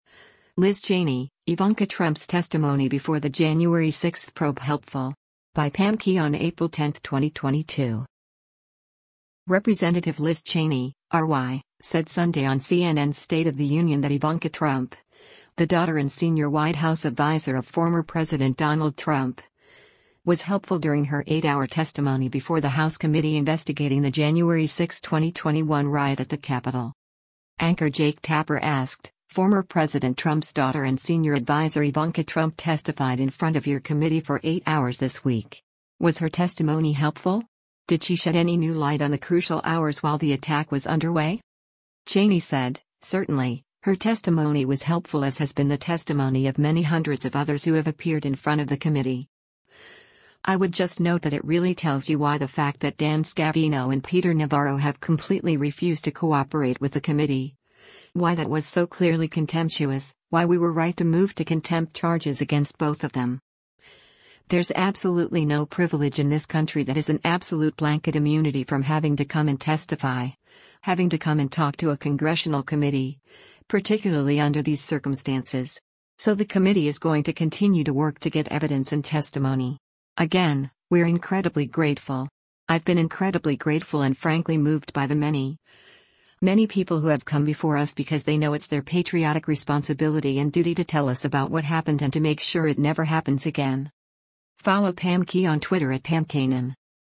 Representative Liz Cheney (R-WY) said Sunday on CNN’s “State of the Union” that Ivanka Trump, the daughter and senior White House adviser of former President Donald Trump, was “helpful” during her eight-hour testimony before the House committee investigating the January 6, 2021 riot at the Capitol.